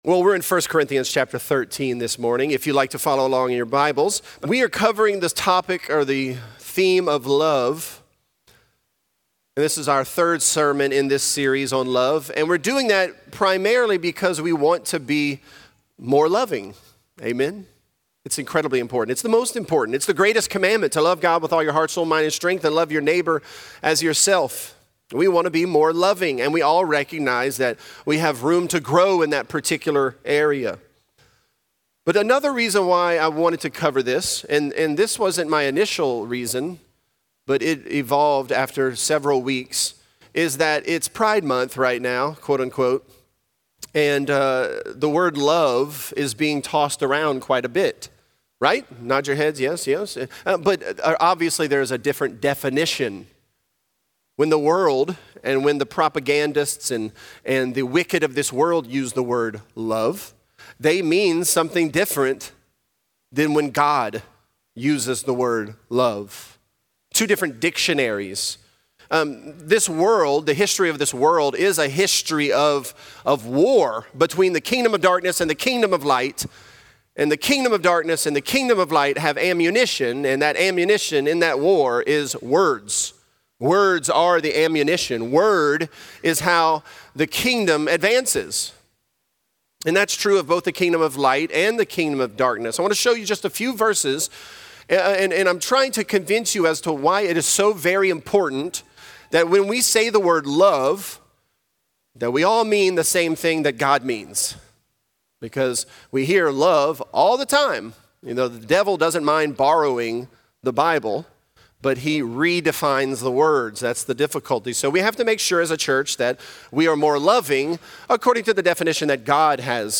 This is a part of our sermon series.